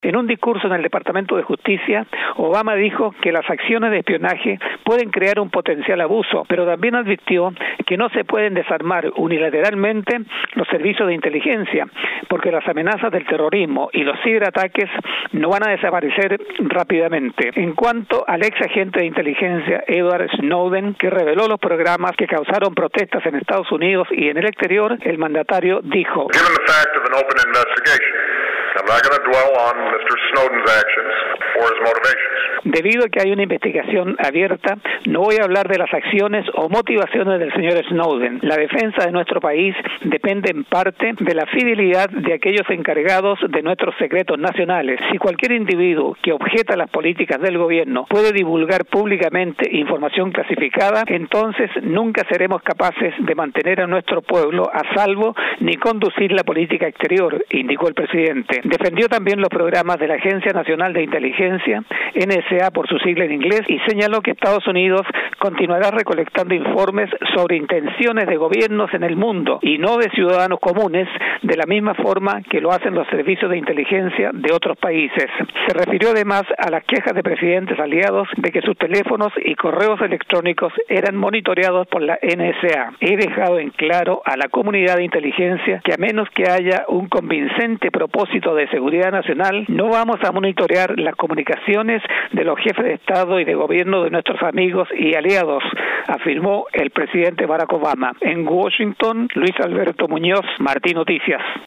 desde Washington